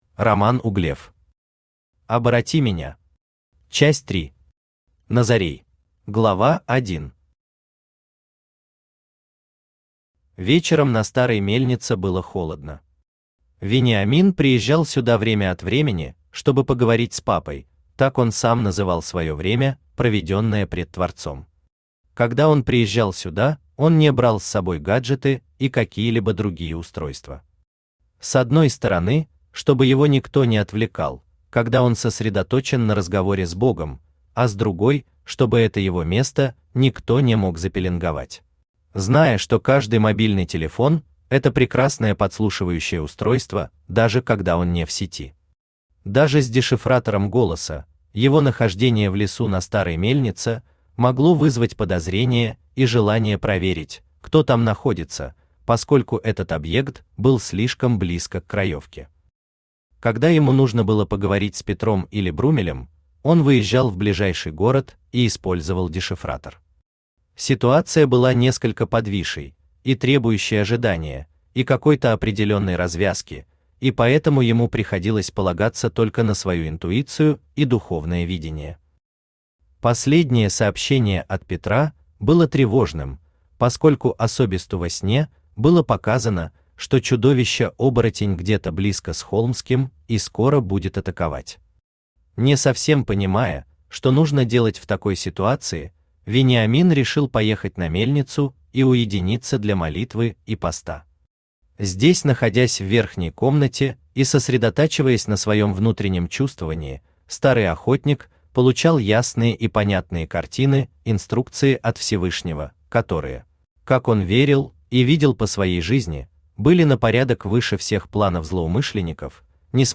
Аудиокнига Обороти меня. Часть 3. Назорей | Библиотека аудиокниг
Назорей Автор Роман Романович Углев Читает аудиокнигу Авточтец ЛитРес.